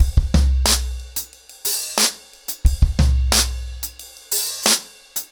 ROOTS-90BPM.17.wav